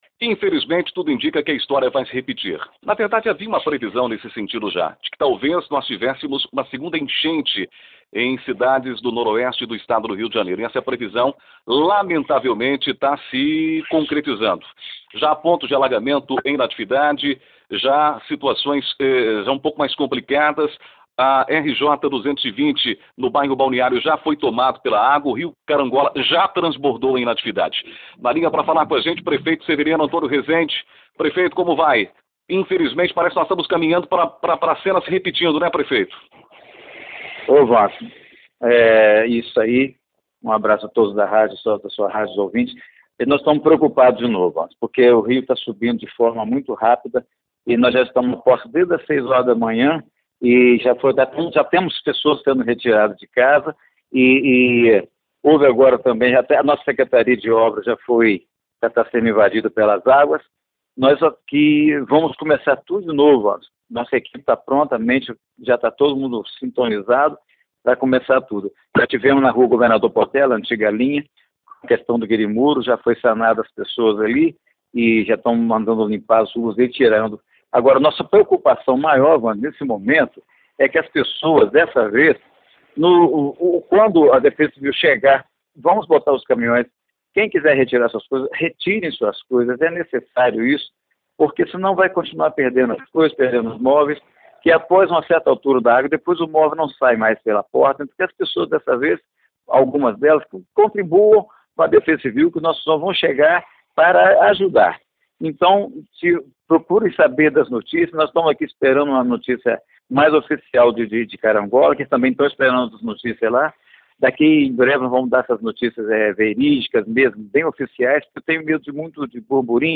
No início da tarde desta quinta-feira (13), o prefeito Severiano Rezende concedeu entrevista à Rádio Natividade, quando falou sobre as chuvas que voltaram a atingir a cidade na madrugada.
13 fevereiro, 2020 DESTAQUE, ENTREVISTAS
ENTREVISTA-SEVERIANO.mp3